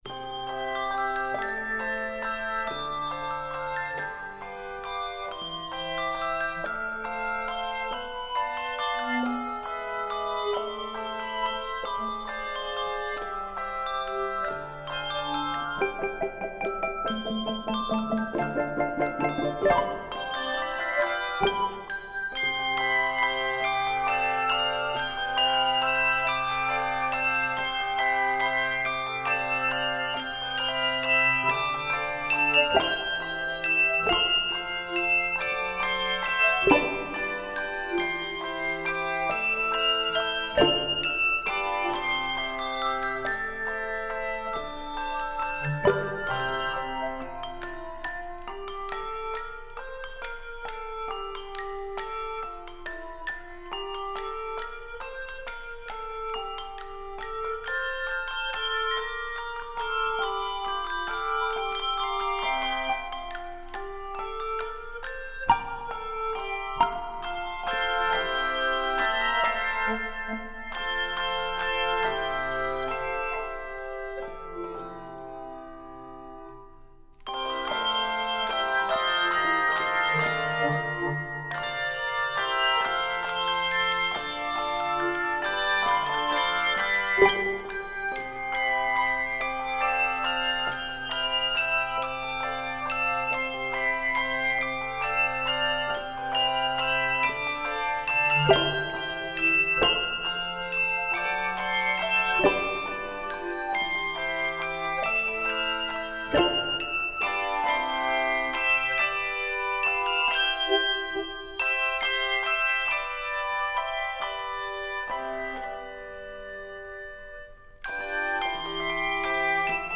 Keys of C Major and F Major.